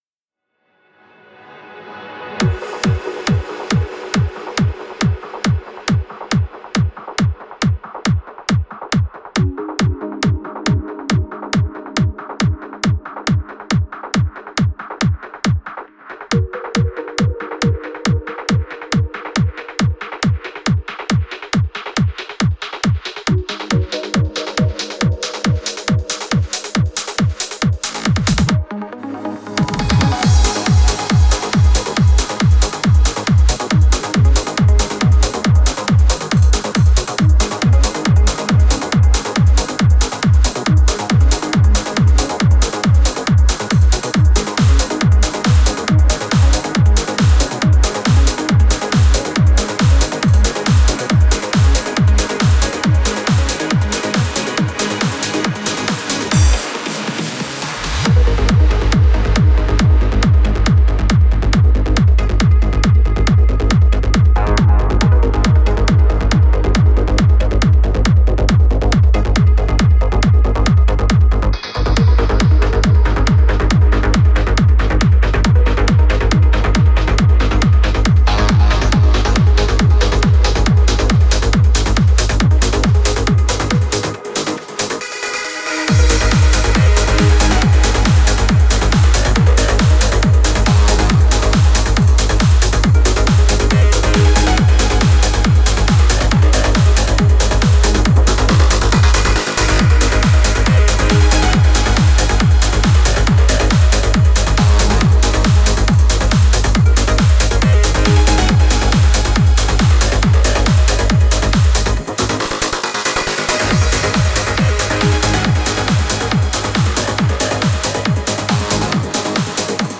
Стиль: Trance / Uplifting Trance